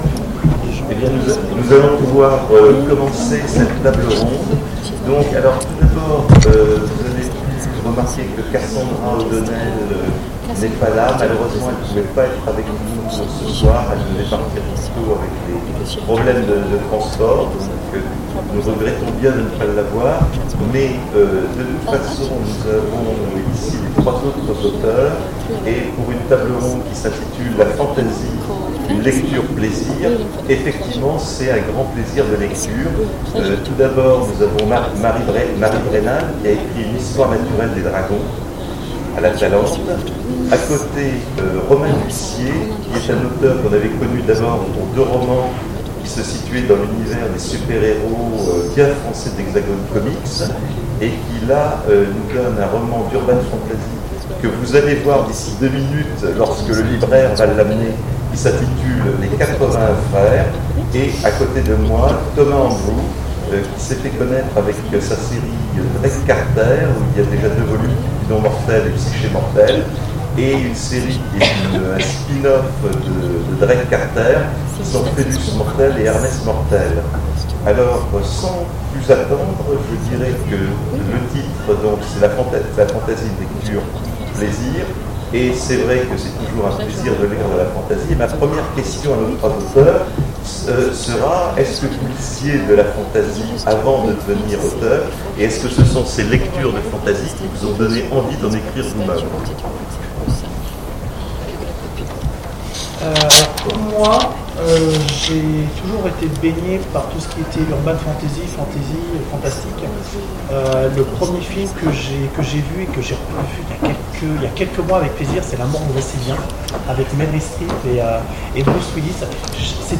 Imaginales 2016 : Conférence La fantasy…
Imaginales_2016_conference_fantasy_lecture_plaisir_ok.mp3